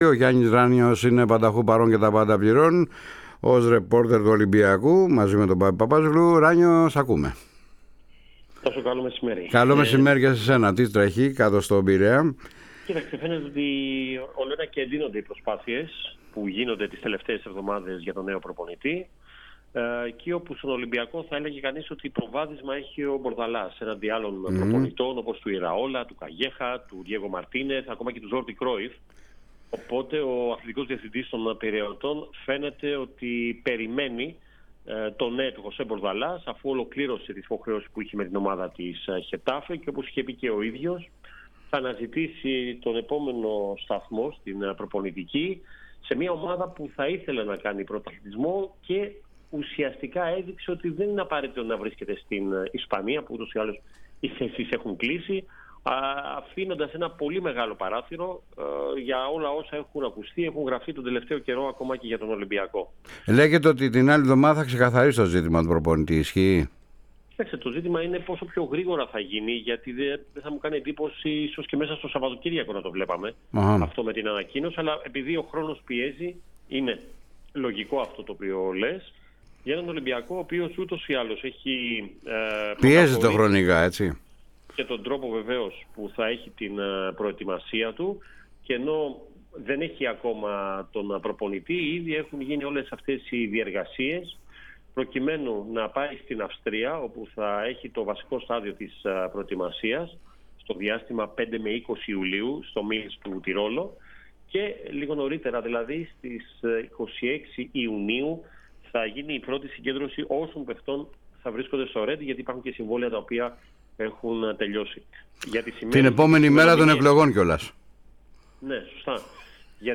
Αναλυτικά όσα είπε στην ΕΡΑ ΣΠΟΡ: